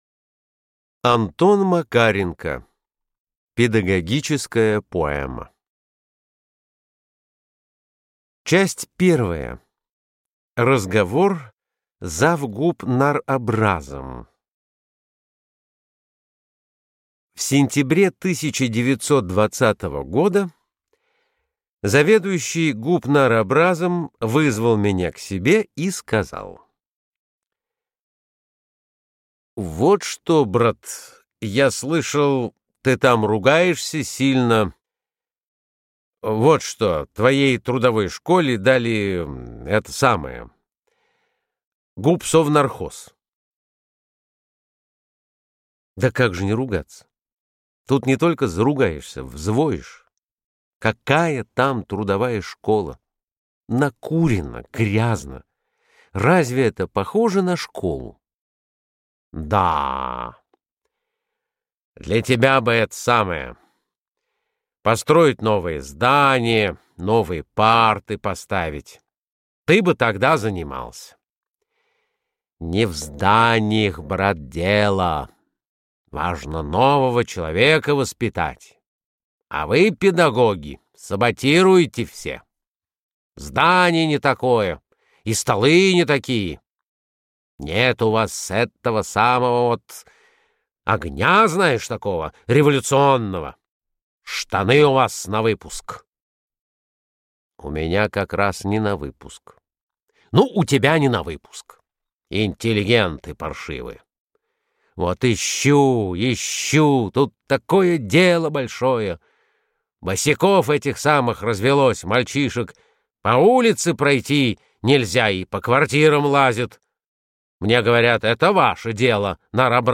Аудиокнига Педагогическая поэма. Часть первая (в сокращении) | Библиотека аудиокниг